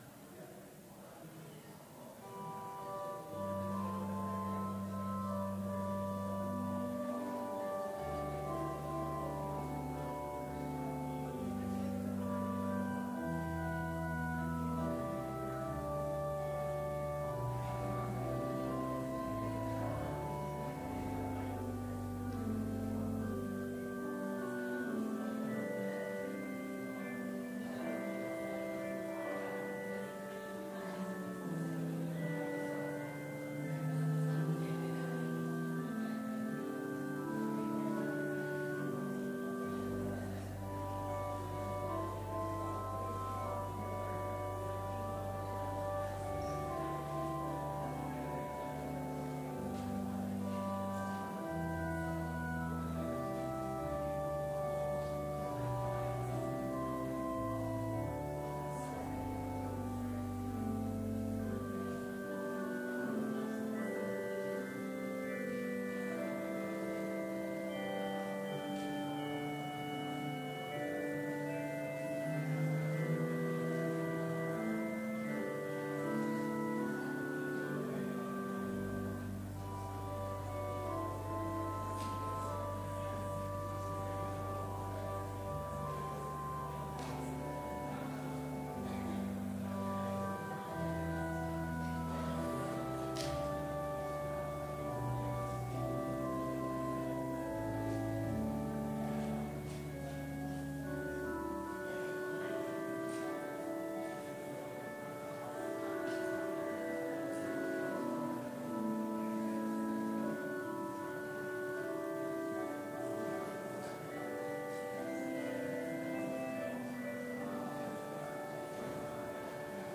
Complete service audio for Chapel - February 13, 2019